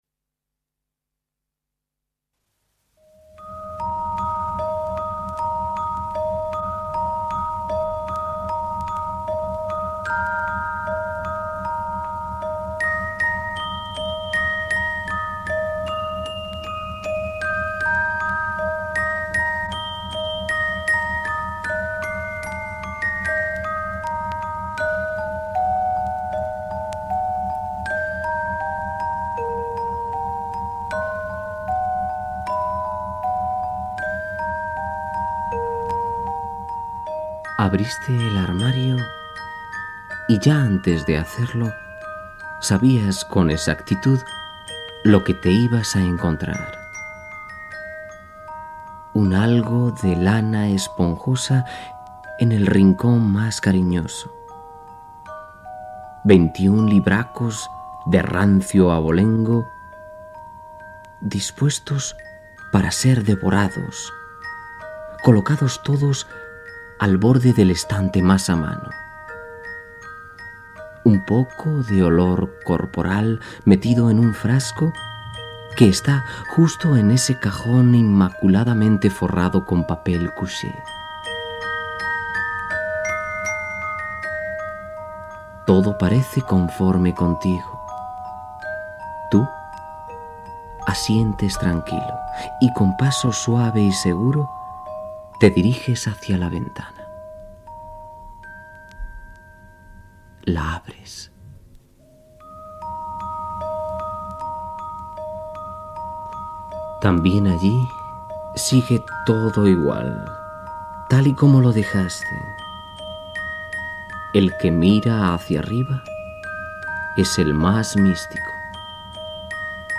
Narració inicial, presentació del programa, fragment musical, narració sobre el "calosaurio"